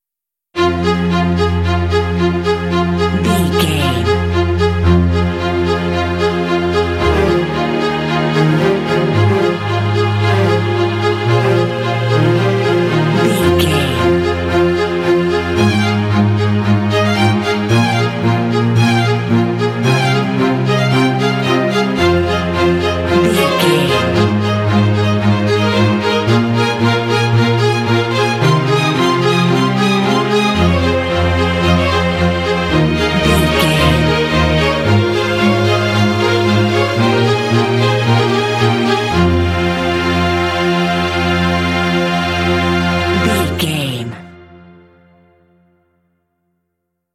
Aeolian/Minor
proud
dramatic
foreboding
tension
suspense
cinematic
film score